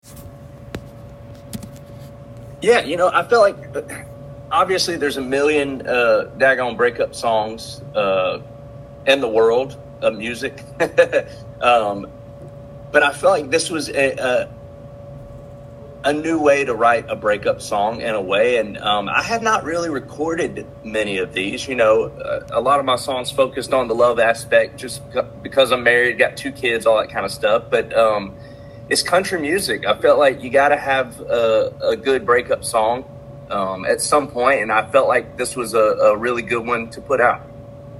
Interview with country star Chris Lane, about his 2023 EP "From Where I'm Sippin'," his single "Find Another Bar," and writing his songs.